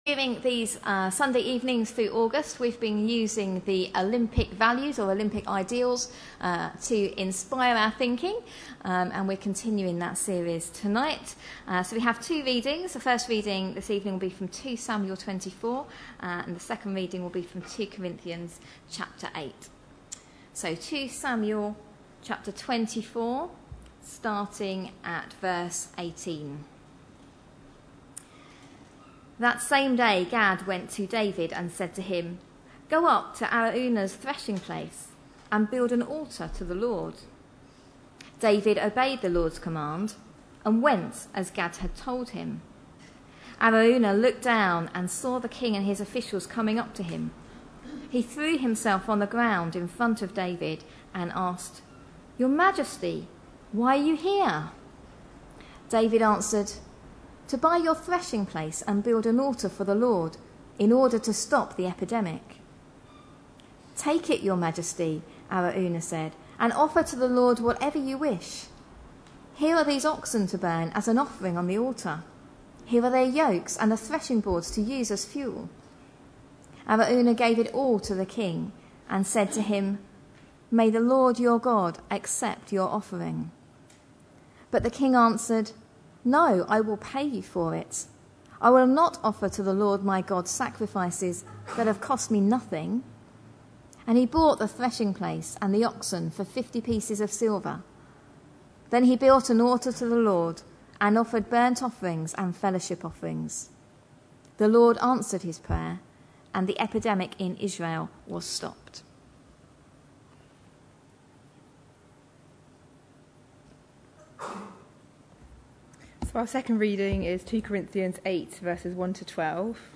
A sermon preached on 12th August, 2012, as part of our Olympic Ideals series.